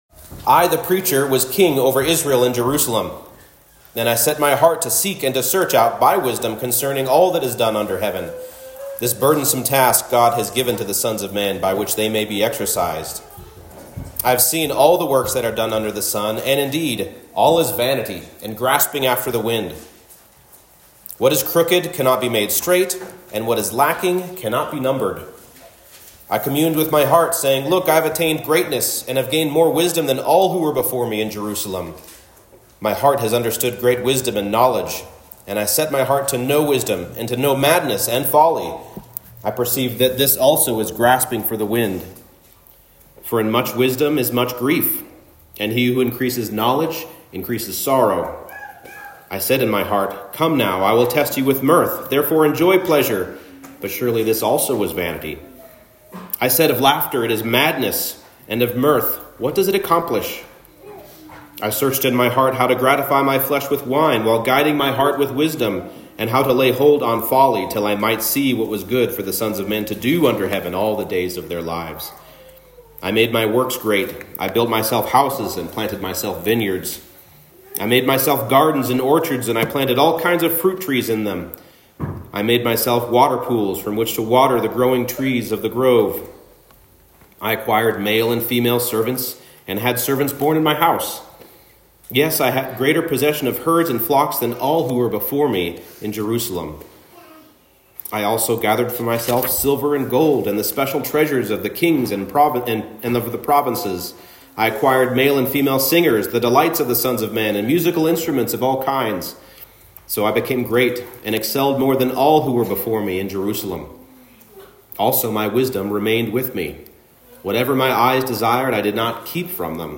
Ecclesiastes 1:12-2:11 Service Type: Morning Service The Preacher searches for satisfaction as only a great king can but comes up short.